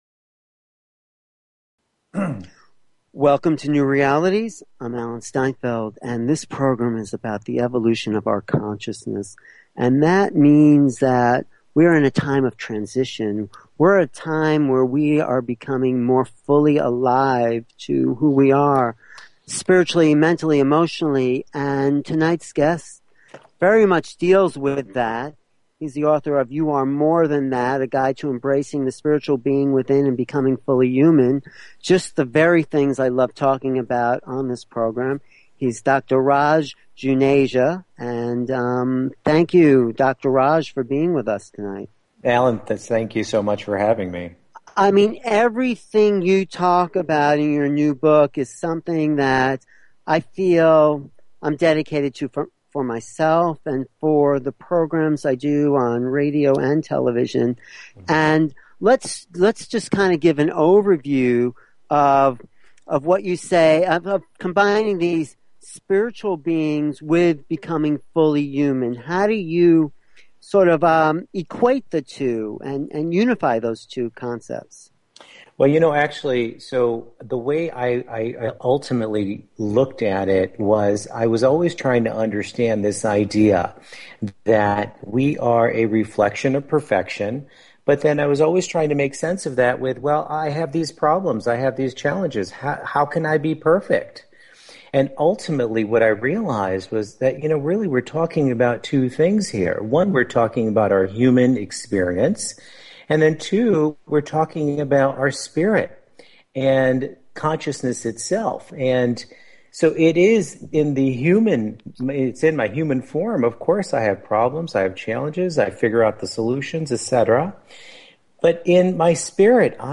Talk Show Episode, Audio Podcast, New_Realities and Courtesy of BBS Radio on , show guests , about , categorized as